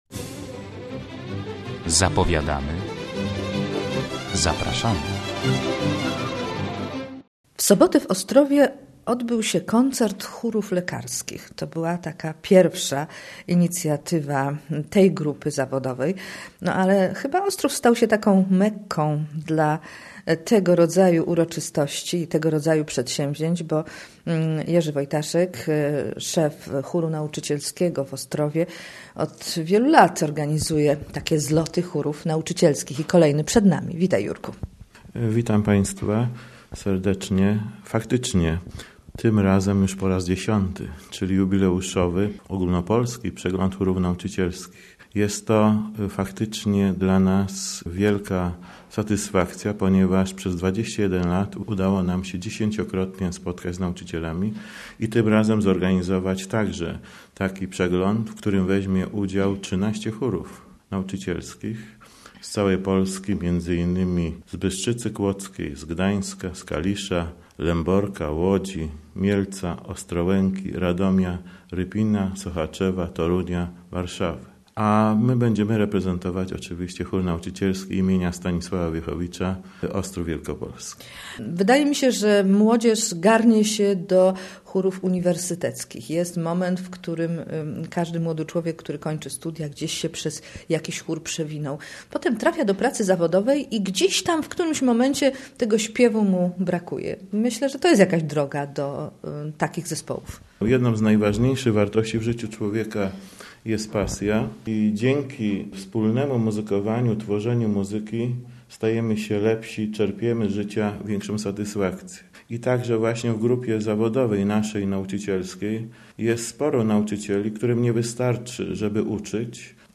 400 śpiewaków z 13 chórów wzięło udział w jubileuszowym X Przeglądzie Chórów Nauczycielskich, który odbył się w ostatni weekend w Ostrowie Wielkopolskim.